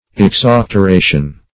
Exauctoration \Ex*auc`tor*a"tion\, n.
exauctoration.mp3